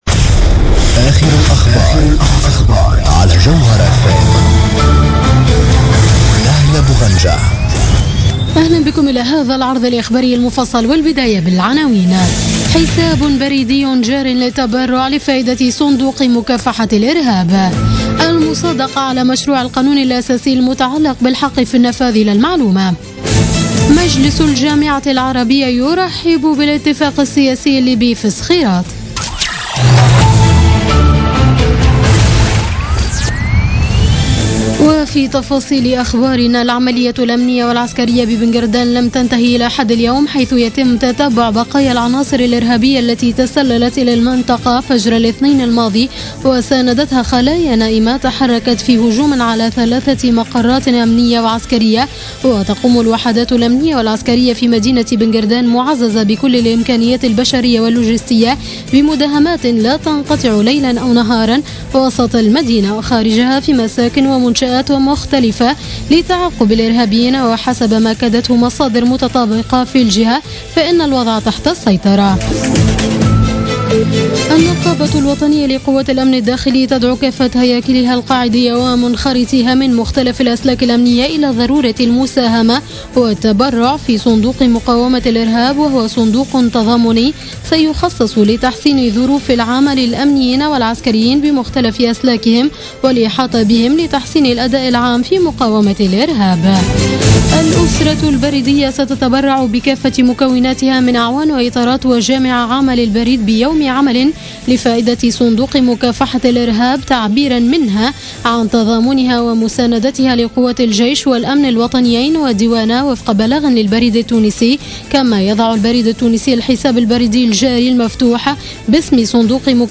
Journal Info 00h00 du samedi 12 Mars 2016